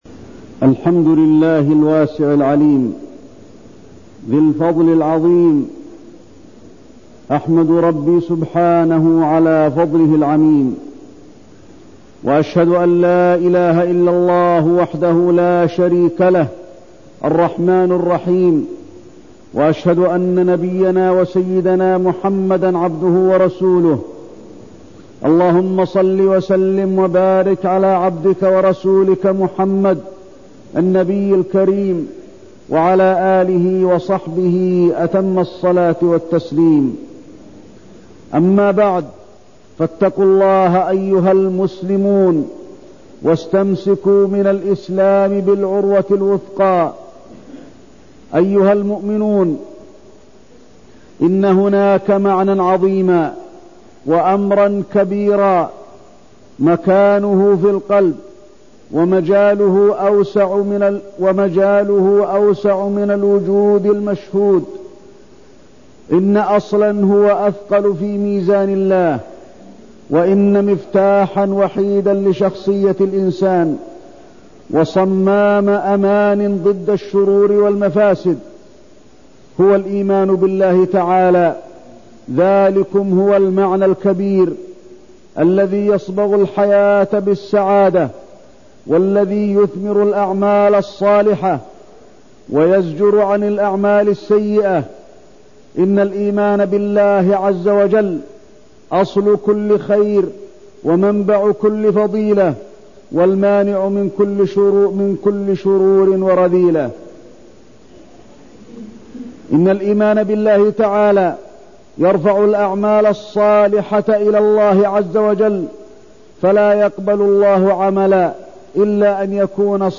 تاريخ النشر ٢ ذو القعدة ١٤١٣ هـ المكان: المسجد النبوي الشيخ: فضيلة الشيخ د. علي بن عبدالرحمن الحذيفي فضيلة الشيخ د. علي بن عبدالرحمن الحذيفي الإيمان